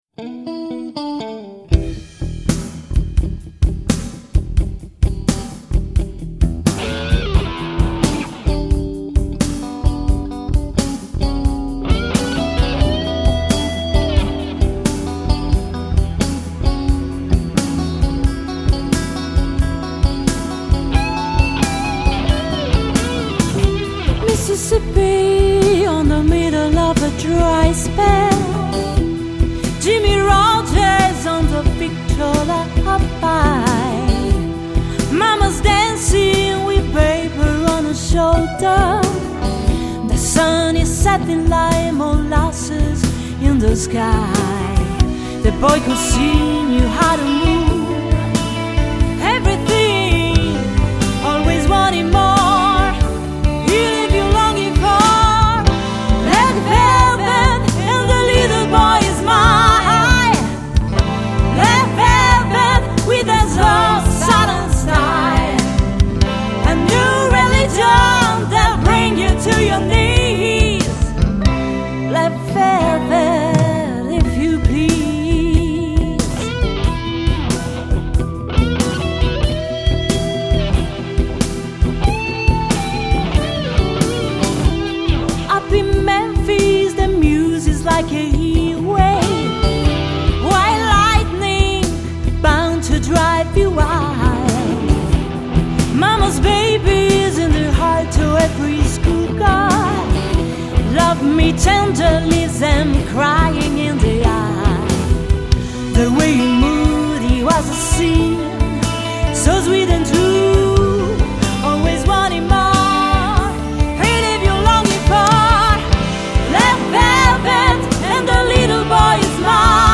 ARE YOU READY TO ROCK ?